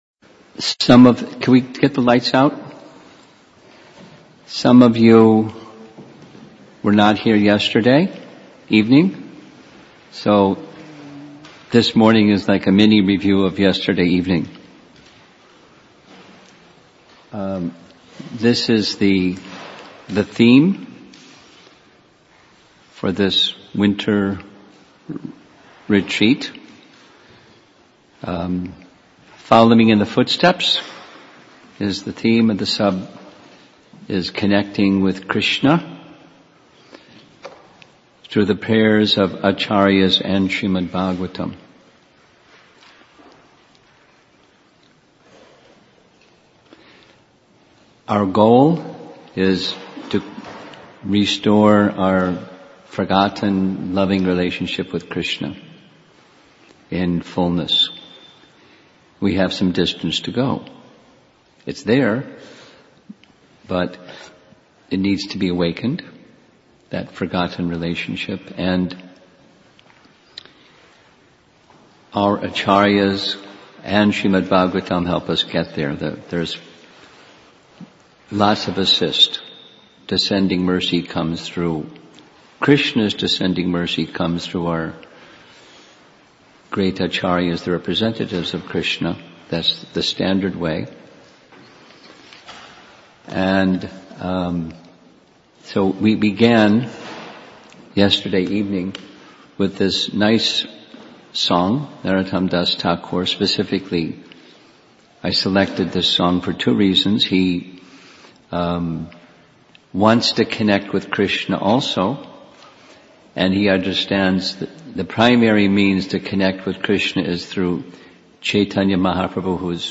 A1–Prayers to Sri Chaitanya Mahaprabhu – Winter Retreat Chicago December 2019